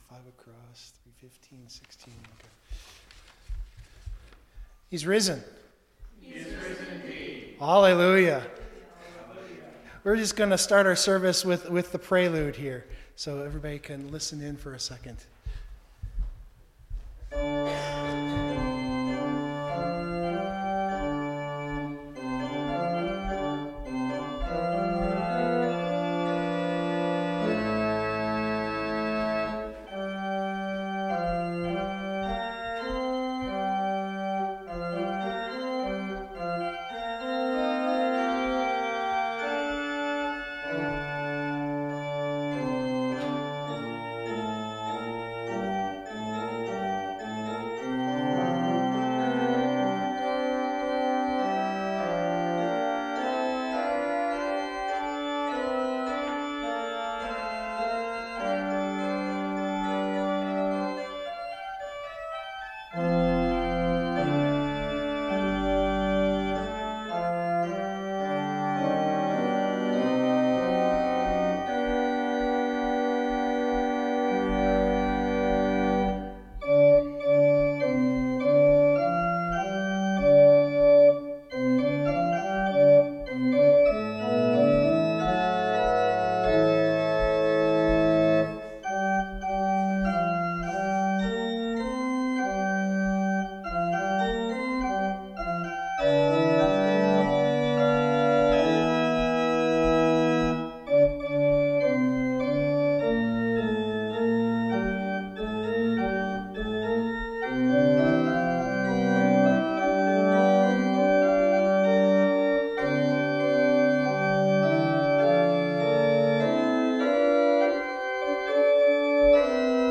I would love to have left some of the music on, but the gap between the live experience of Easter music and our poor recording of it is just too much. So, it is just the lessons and sermon.